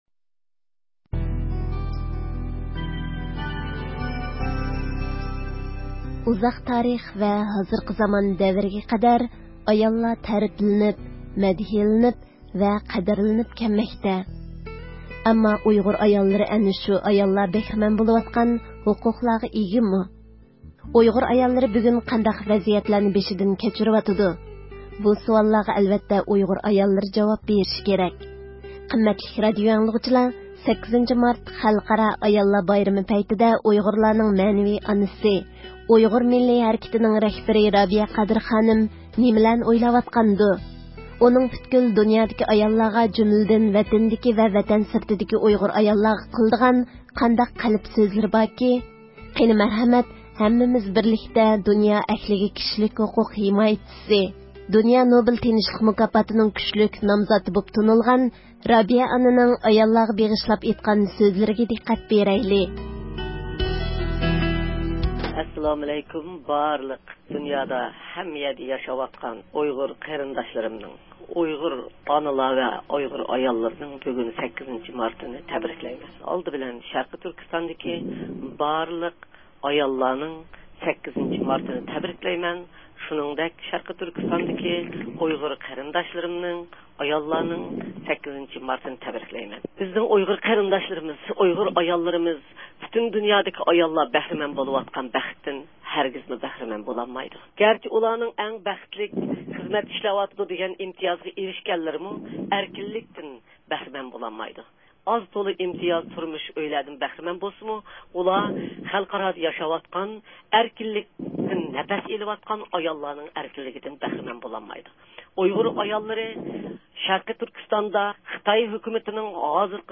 ئۈچ كىشىلىك تېلېفون سۆھبىتى